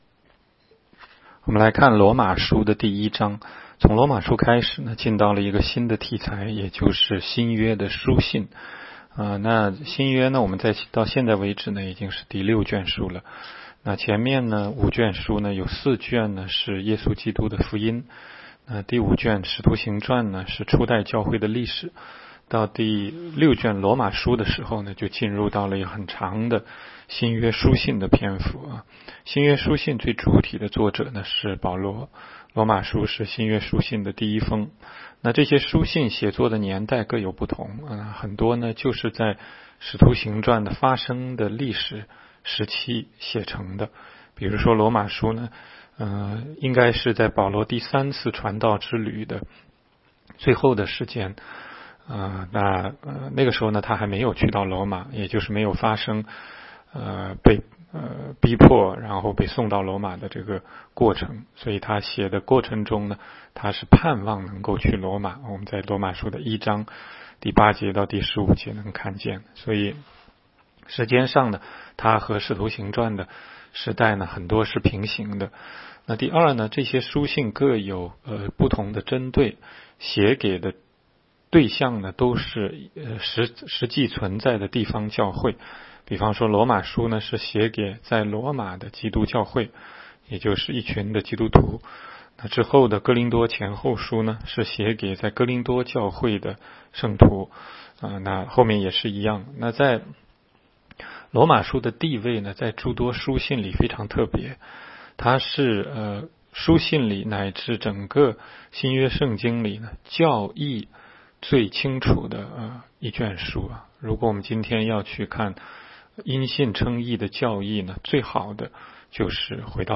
16街讲道录音 - 每日读经 -《罗马书》1章